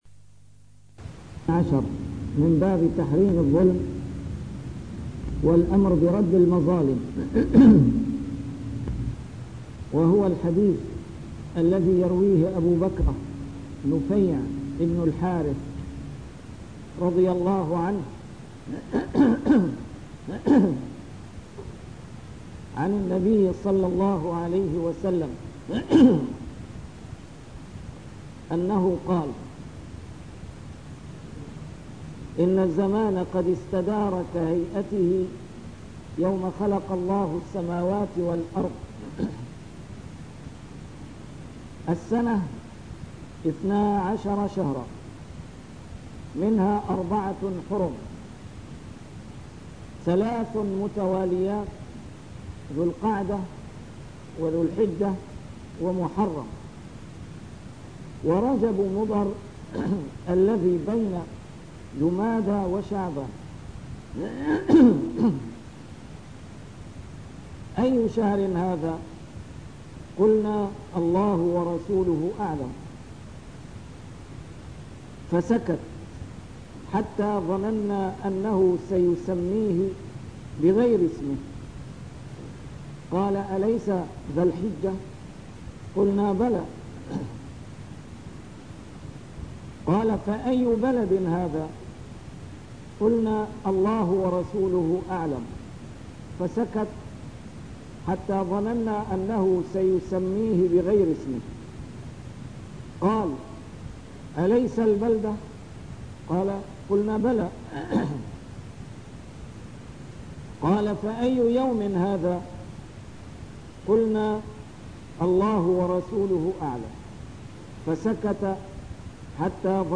A MARTYR SCHOLAR: IMAM MUHAMMAD SAEED RAMADAN AL-BOUTI - الدروس العلمية - شرح كتاب رياض الصالحين - 321- شرح رياض الصالحين: تحريم الظلم